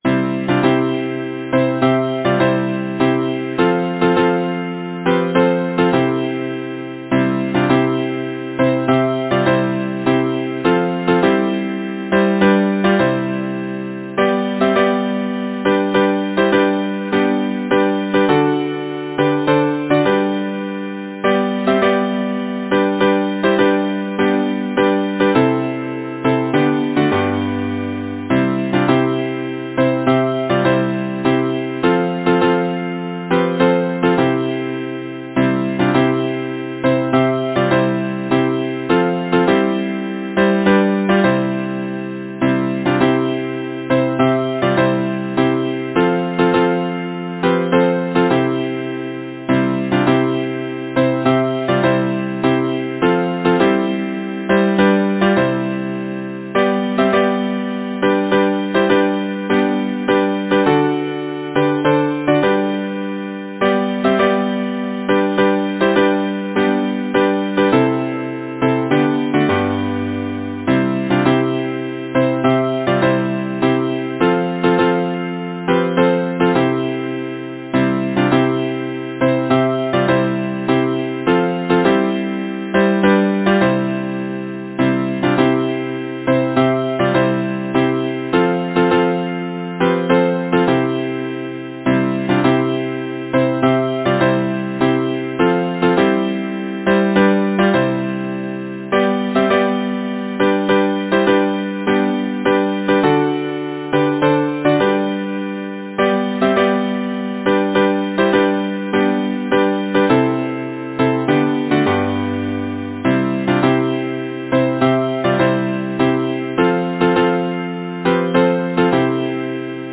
Title: Spring Composer: John Harrison Tenney Lyricist: Frances Elizabeth Swift Number of voices: 4vv Voicing: SATB Genre: Secular, Partsong, Glee
Language: English Instruments: A cappella